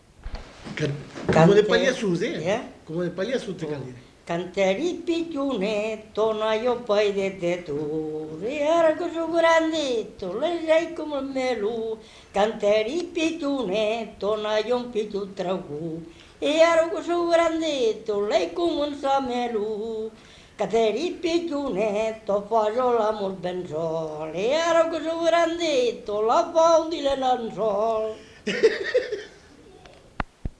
Genre : chant
Effectif : 1
Type de voix : voix d'homme
Production du son : chanté
Ecouter-voir : archives sonores en ligne